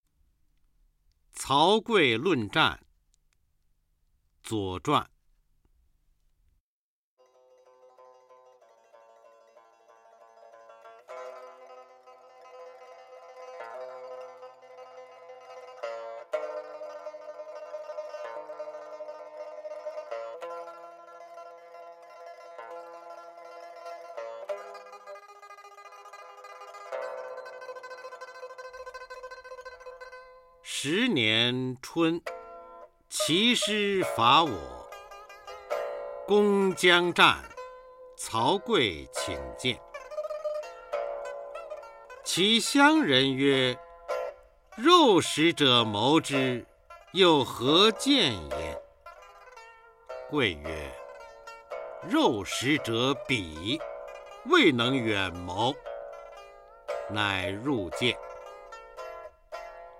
初中生必背古诗文标准朗诵（修订版）（1）-04-方明-曹刿论战 《左传》春秋